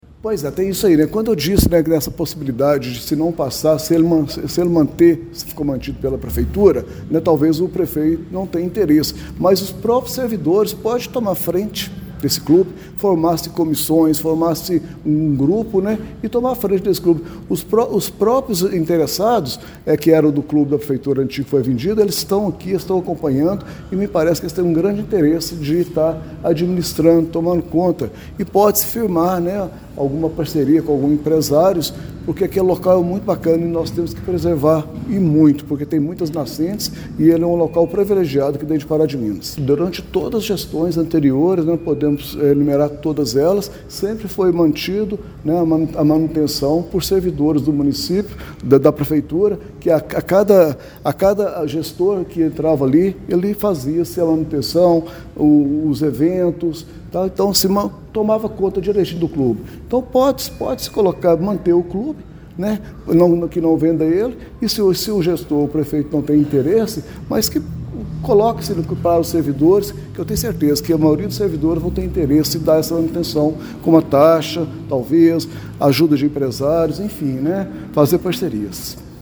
Durante a sessão realizada nesta terça-feira (07), o Projeto de Lei Ordinária nº 15/2026, que autoriza a prefeitura a vender o imóvel do extinto Clube Arpa, teve sua tramitação interrompida após um pedido de vista, revelando profundas divergências entre os parlamentares sobre o destino da área.